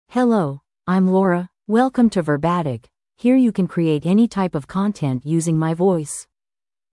FemaleEnglish (United States)
Laura is a female AI voice for English (United States).
Voice sample
Female
Laura delivers clear pronunciation with authentic United States English intonation, making your content sound professionally produced.